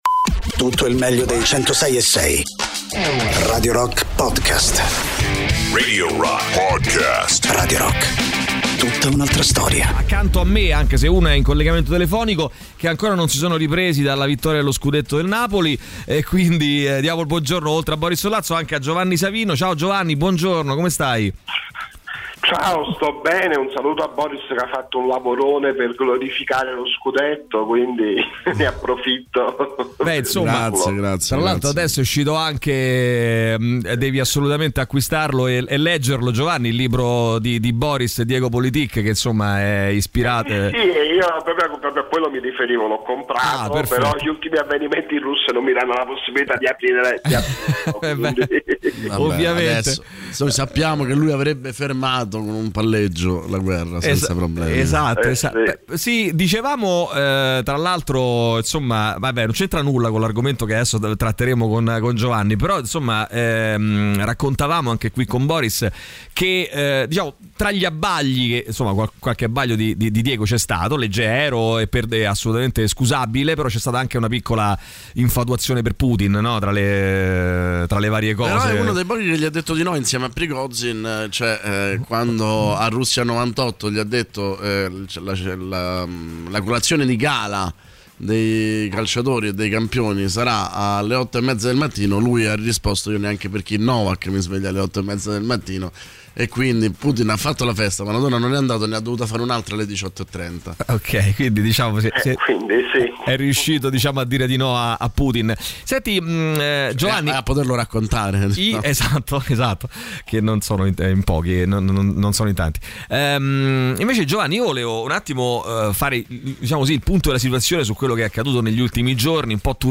Interviste
in collegamento telefonico